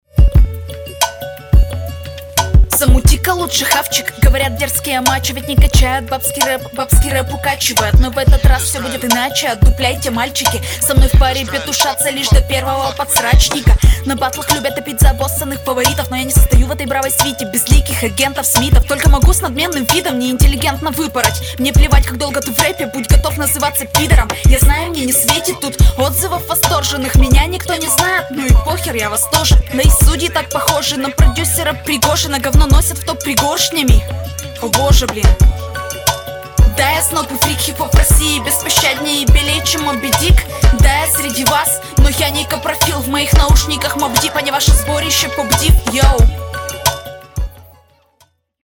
:) Флоу не без косяков, но в целом нормуль, хотя текст получше. И давай побольше уверенности, типа ты берёшь этот баттл, а то пока тускловато звучишь.